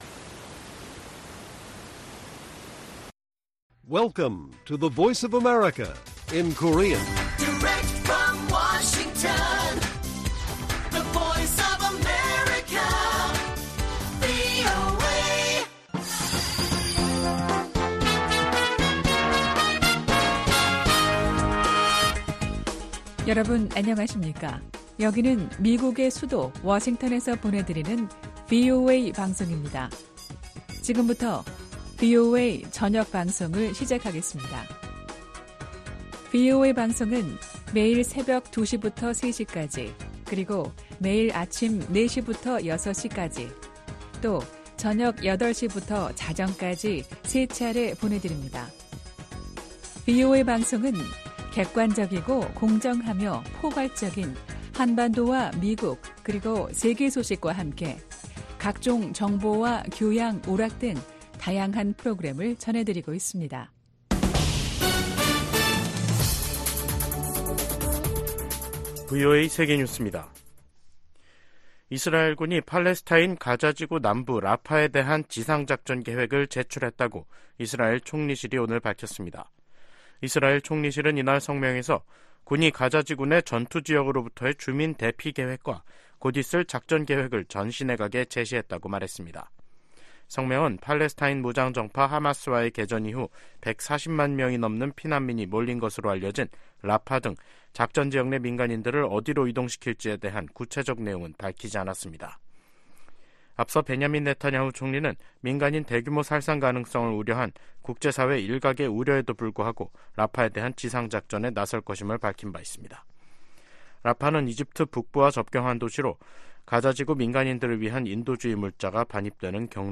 VOA 한국어 간판 뉴스 프로그램 '뉴스 투데이', 2024년 2월 26일 1부 방송입니다. 주요7개국(G7) 정상들이 우크라이나 전쟁 2주년을 맞아 북한-러시아 탄도미사일 거래를 규탄했습니다.